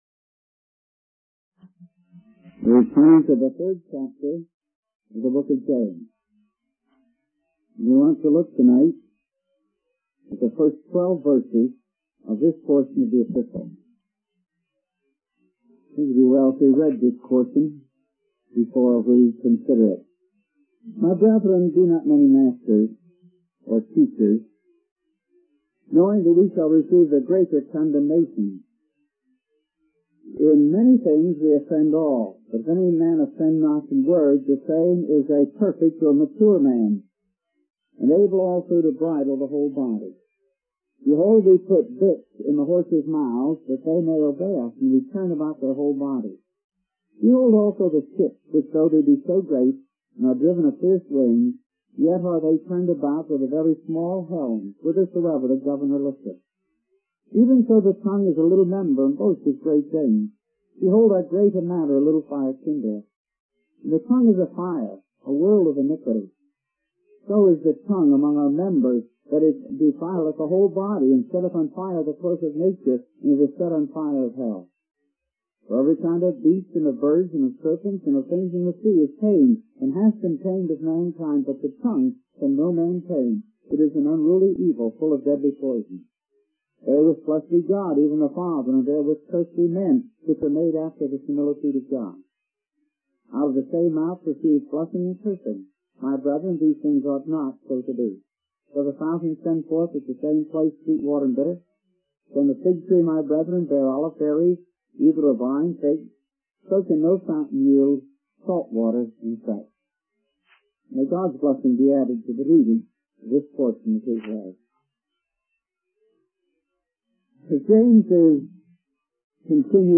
In this sermon, the preacher uses two illustrations to emphasize the power of the tongue.